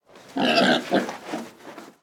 Gruñido de un cerdo